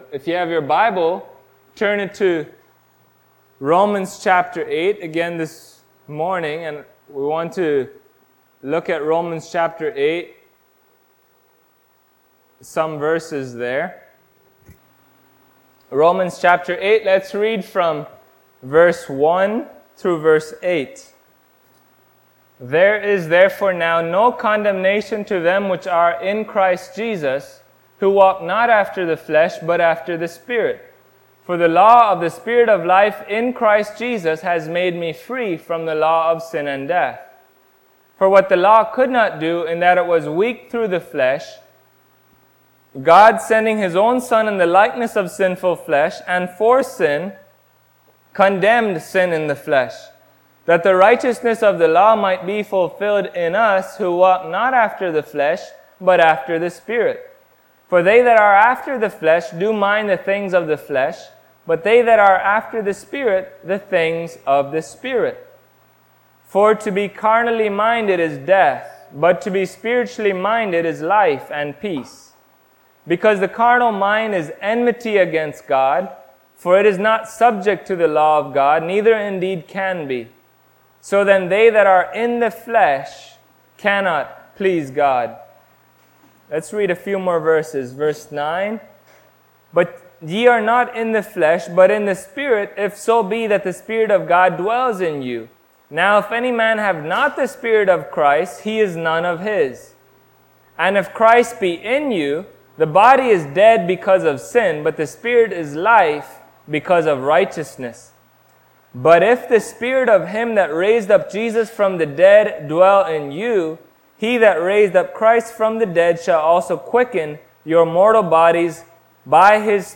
Passage: Romans 8:2-8 Service Type: Sunday Morning